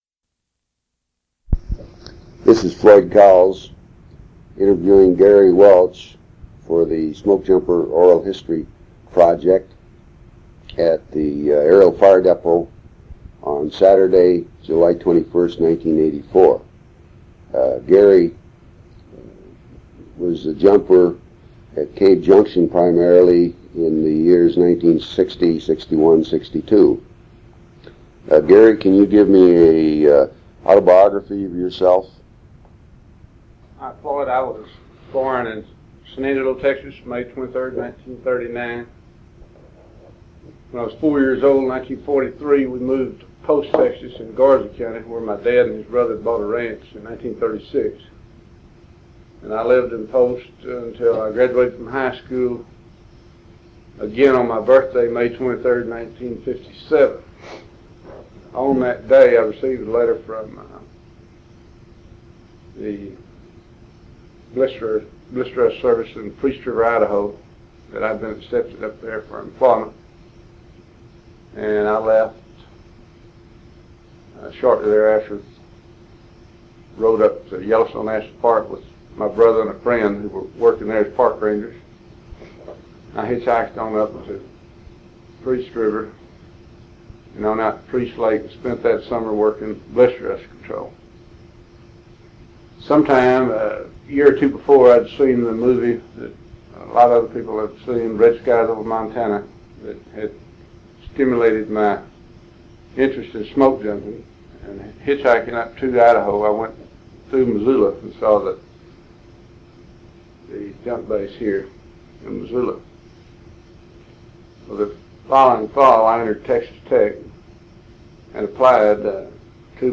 Oral History
1 sound cassette (85 min.) : analog